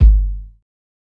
KICK_IBANG.wav